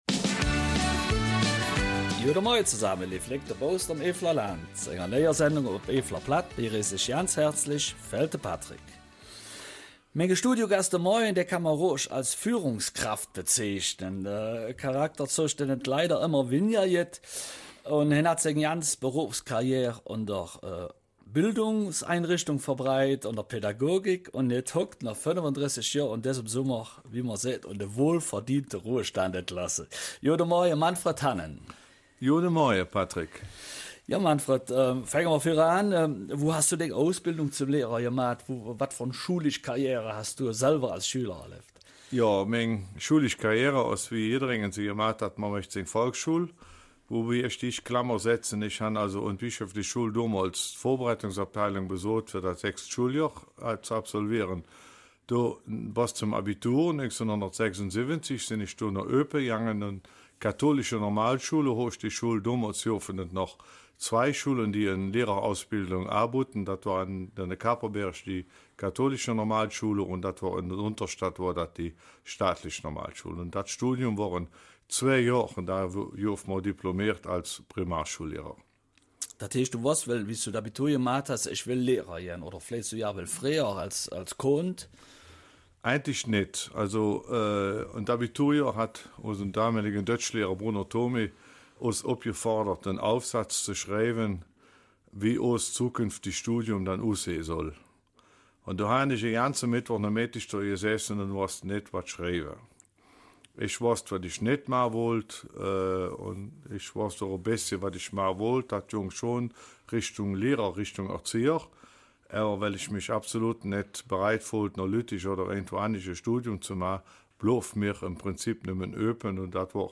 Eifeler Mundart: 45 Jahre im Bildungswesen 14.